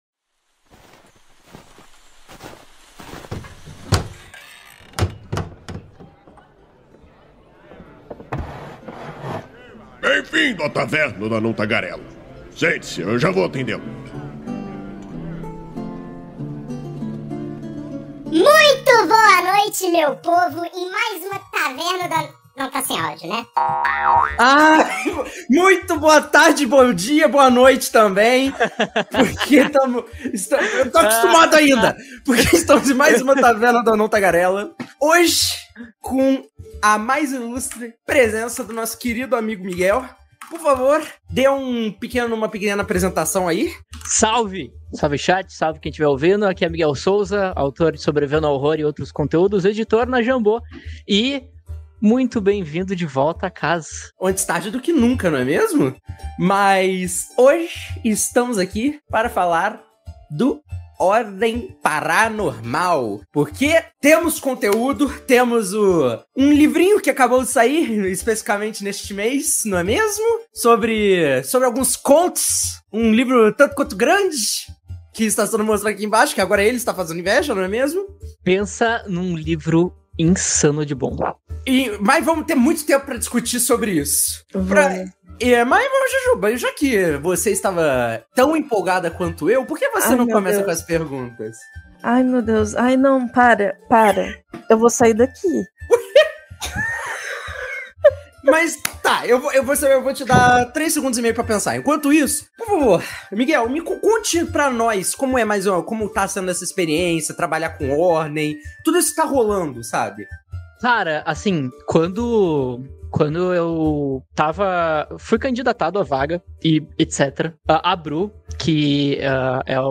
Venha entender melhor sobre as criaturas que existem nesse mundo e o processo criativo por tras delas e saber um pouco mais sobre como o romance “O Espreitador” expande esse universo.. A Taverna do Anão Tagarela é uma iniciativa do site Movimento RPG, que vai ao ar ao vivo na Twitch toda a segunda-feira e posteriormente é convertida em Podcast.